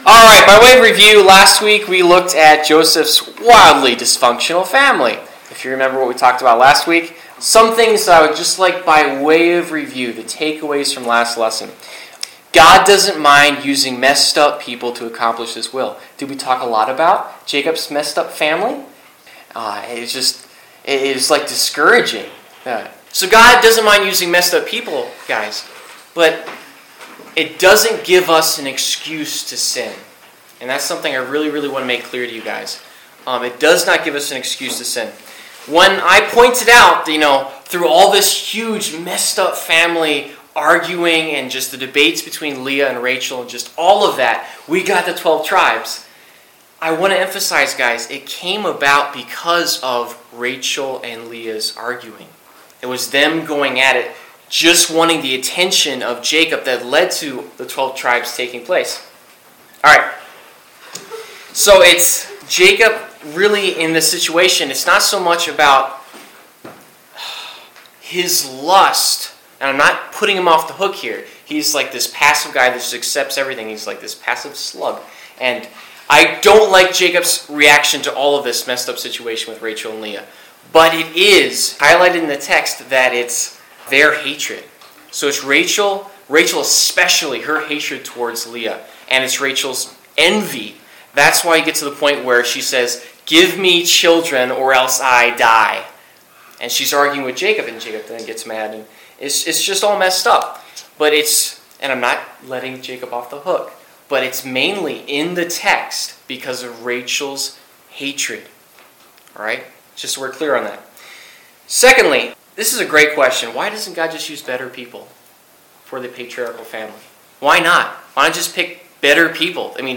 Service Type: Wednesday Night - Youth Group Topics: Betrayal , Injustice , Malevolence , Obedience , Responsibility